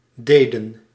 Ääntäminen
Ääntäminen Tuntematon aksentti: IPA: /ˈdeːdə(n)/ Haettu sana löytyi näillä lähdekielillä: hollanti Käännöksiä ei löytynyt valitulle kohdekielelle.